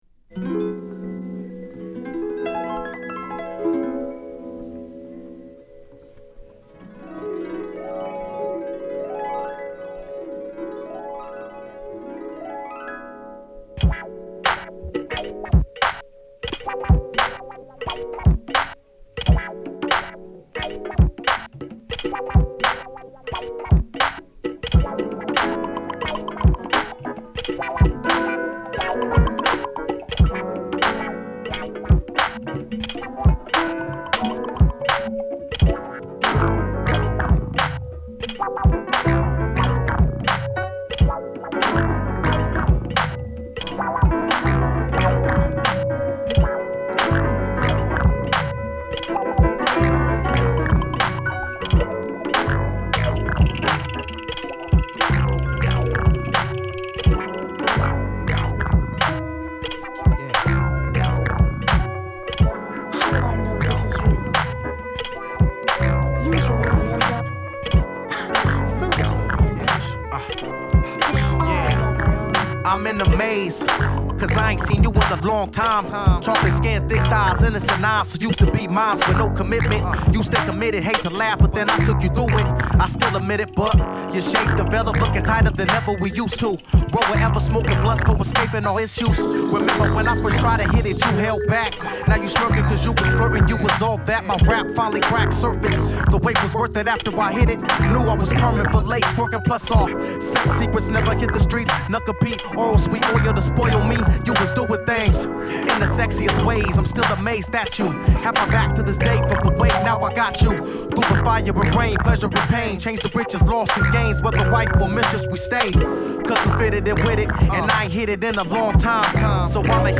Hip Hop Mix